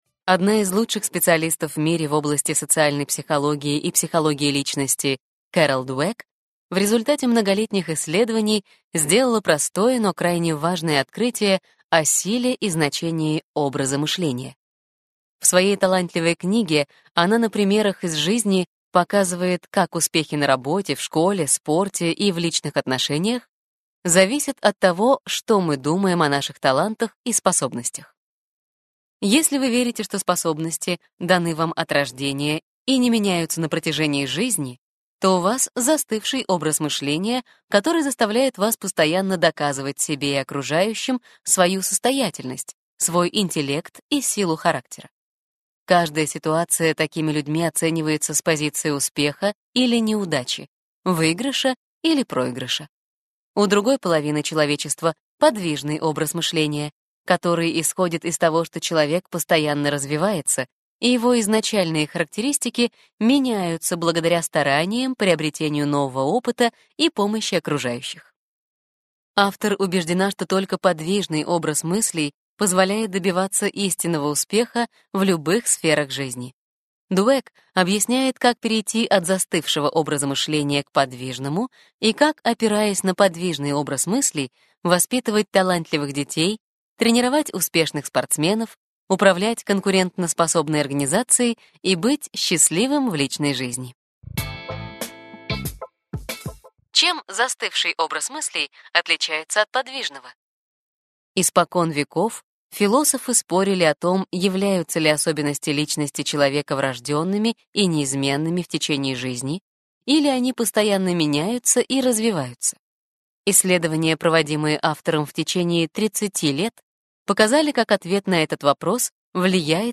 Аудиокнига Лучшие идеи по развитию личной эффективности. 10 книг в одной | Библиотека аудиокниг